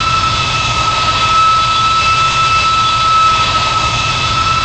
Add external IAE sounds
v2500-spool.wav